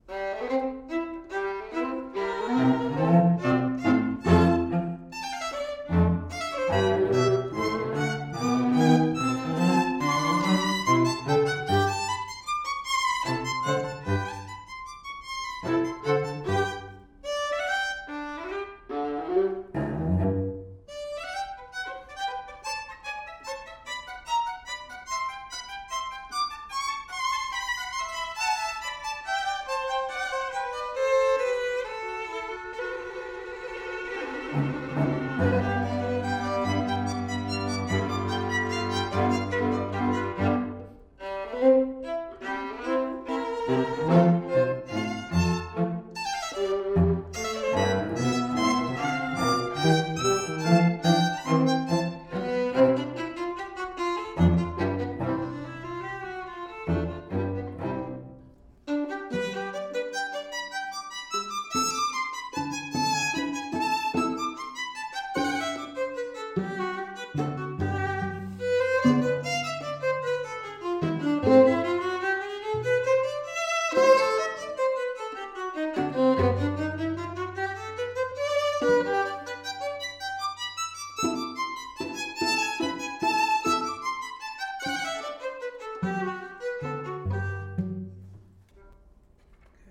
Next comes a lively and original sounding Menuetto, allegro.